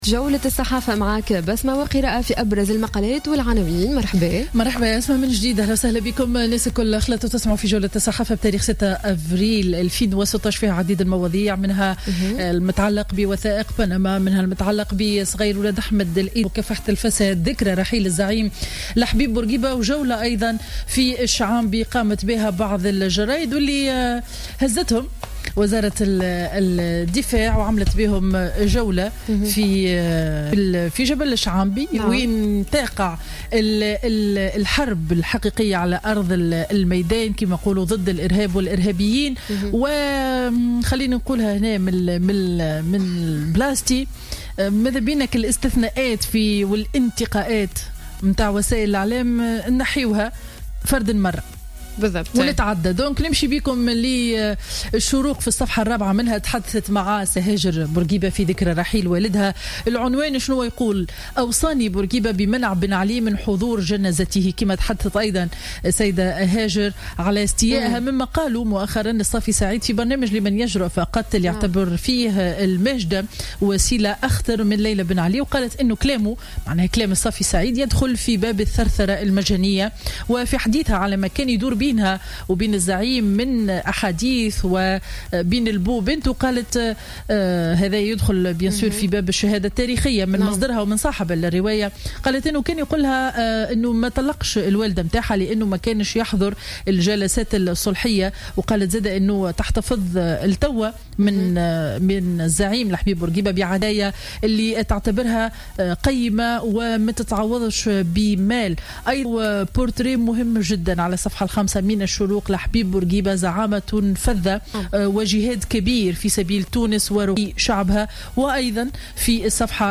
Revue de presse du mercredi 6 avril 2016